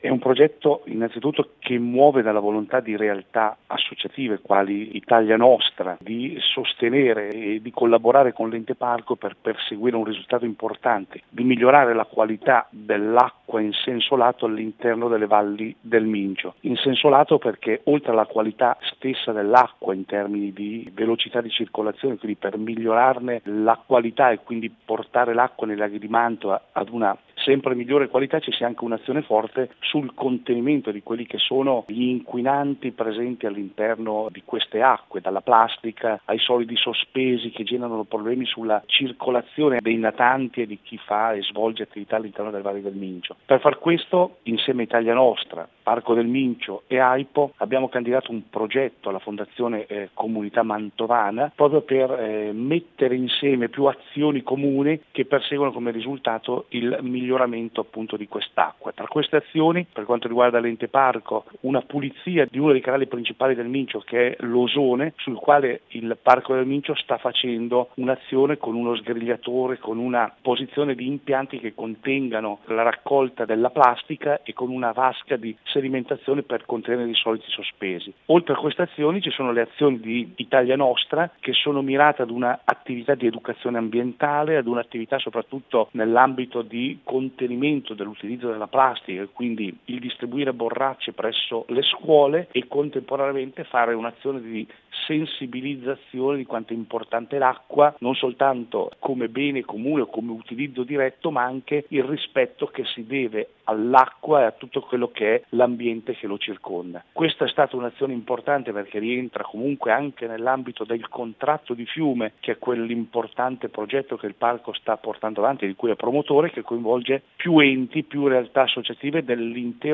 Il progetto si articola in due principali livelli di azione, come ci spiega il presidente del Parco Del Mincio Maurizio Pellizzer: